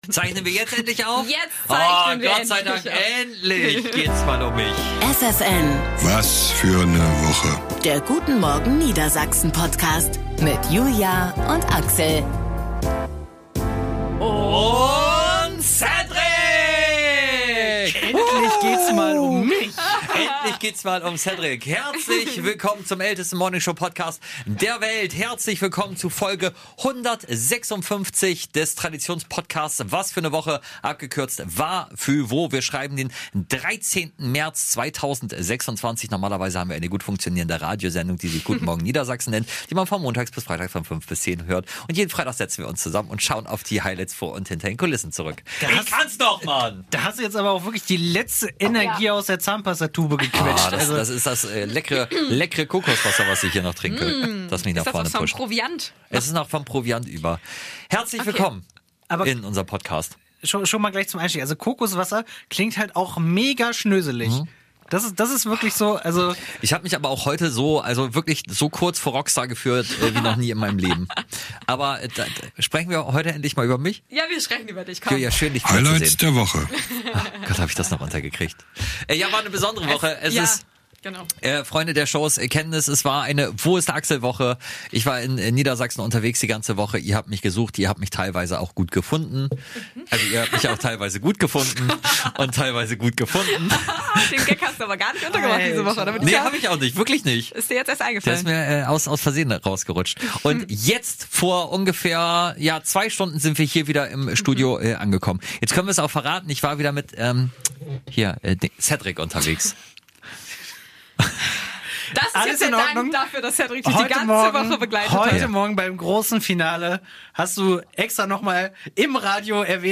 Interview mit Madsen in voller Länge.